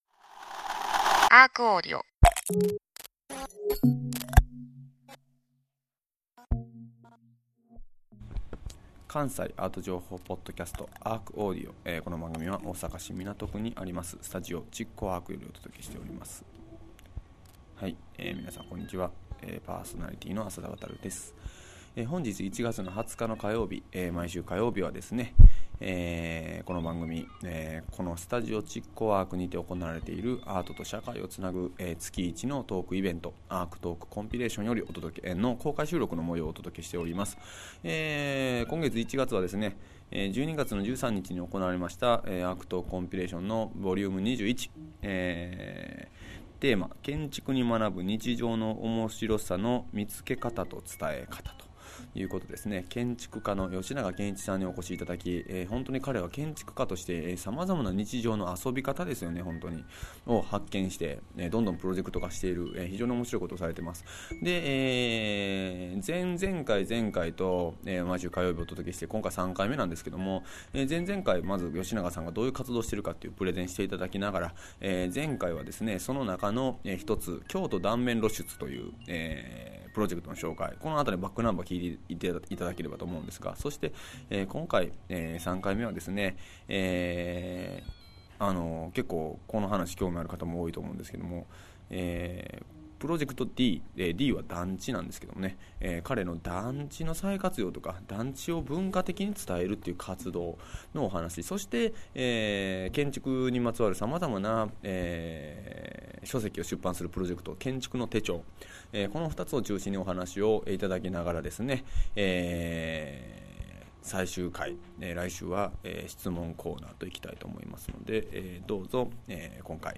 毎週火曜日は築港ARCにて毎月開催されるアートと社会を繋ぐトークイベント「ARCトークコンピレーション」の模様を全4回に分けてお届けします。今月のテーマはずばり！「建築から見つめる日常」。